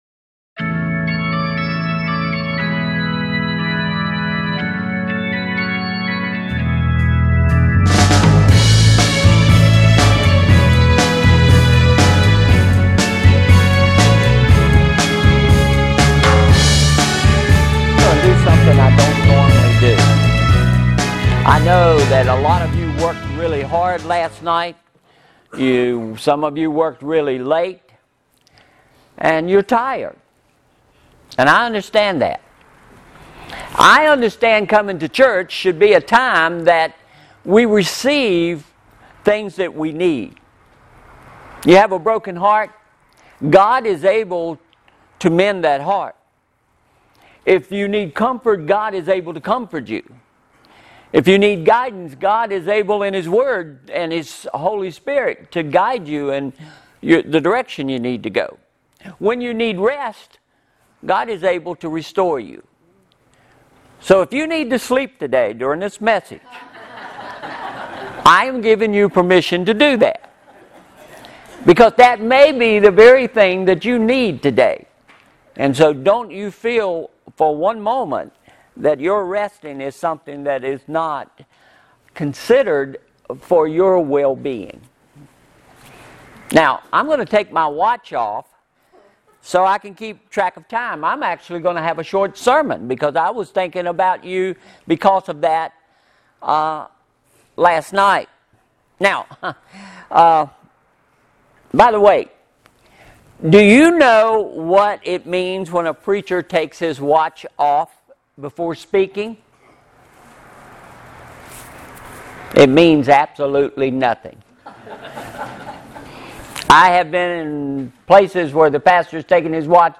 -------------------------- Sermon Synopsis -------------------------